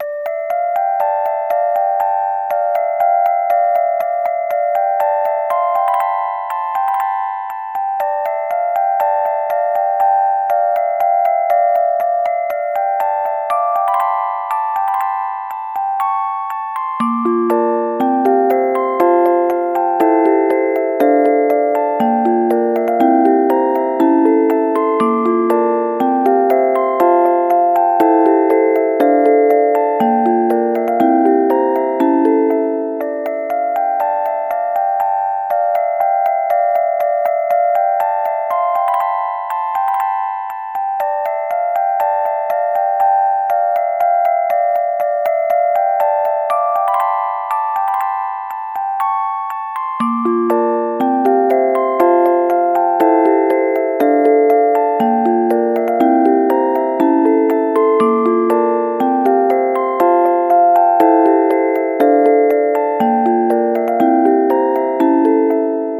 冒頭がカノンになっている切ない系オルゴール曲です